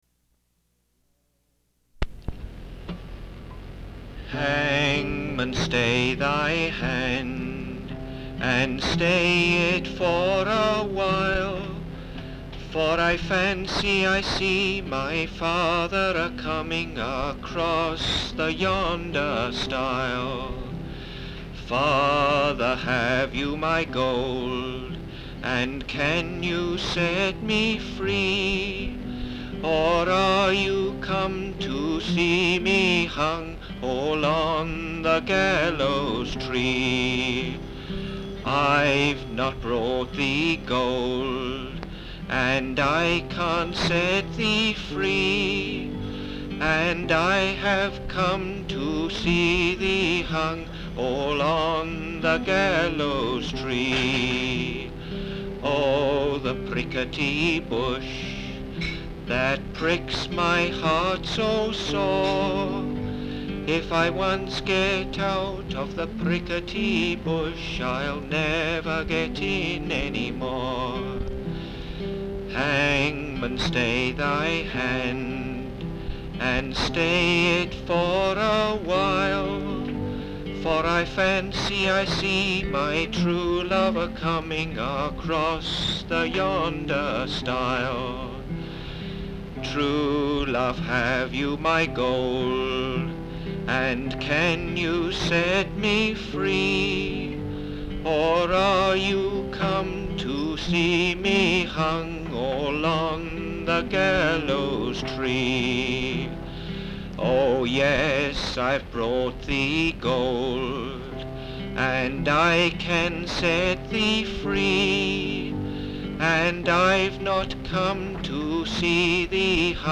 Folk songs, English--Vermont (LCSH)
sound tape reel (analog)
Location Marlboro, Vermont